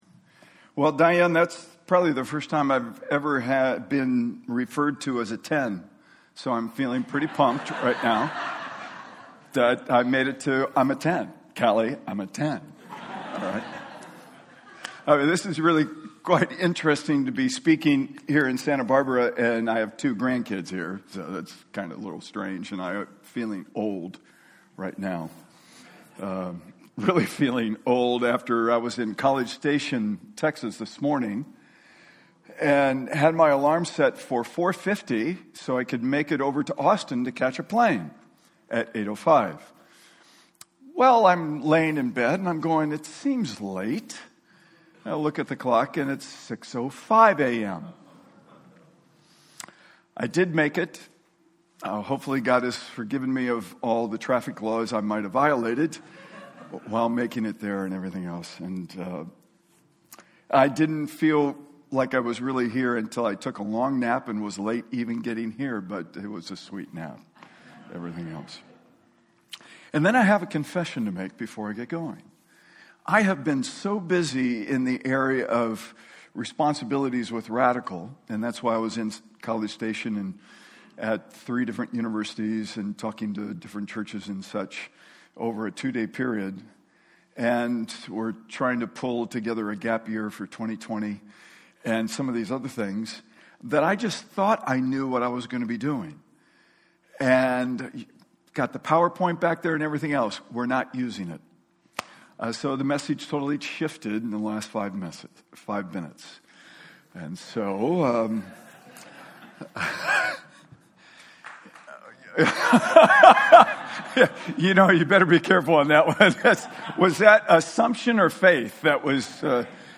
Speaker: Guest Preacher | Series: Missions Conference